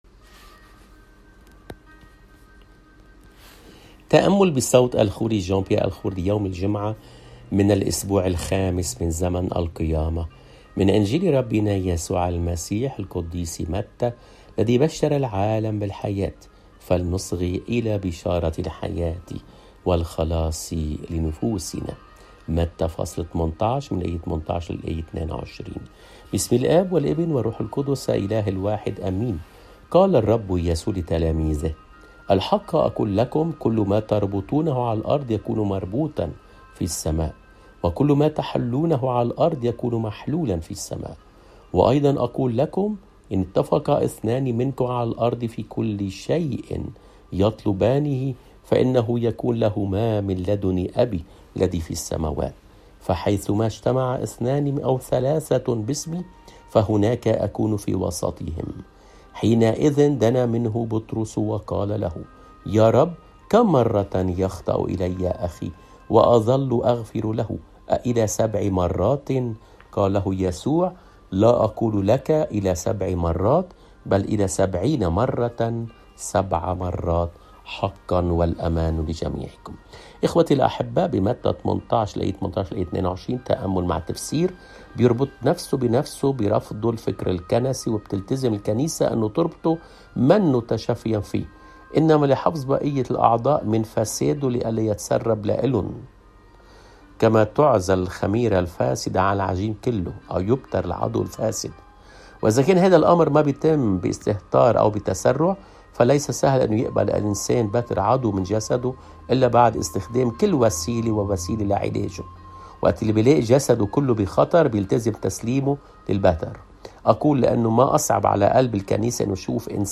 الإنجيل